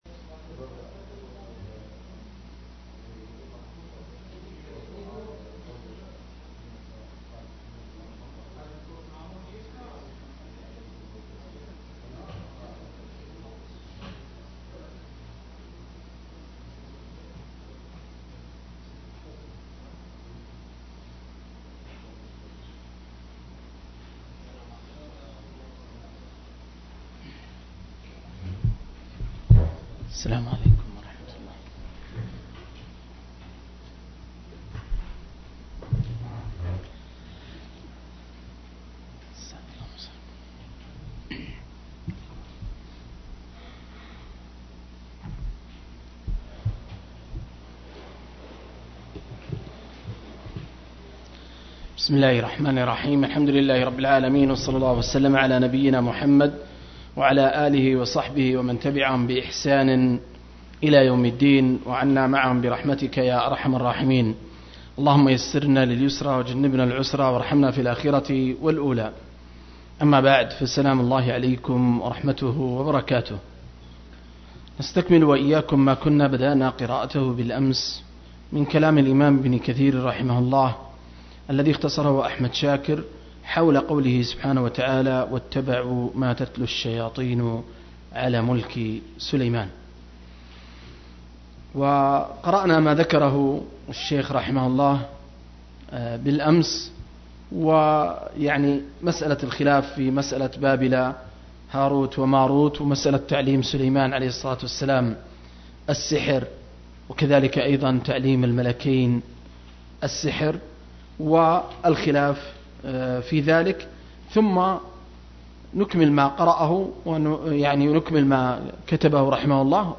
023- عمدة التفسير عن الحافظ ابن كثير – قراءة وتعليق – تفسير سورة البقرة (الآيتين 102-103)